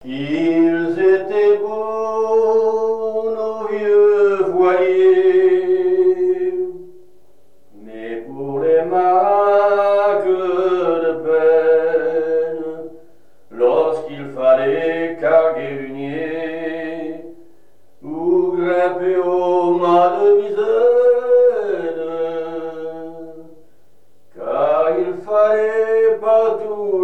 Mémoires et Patrimoines vivants - RaddO est une base de données d'archives iconographiques et sonores.
Genre strophique
Pièce musicale inédite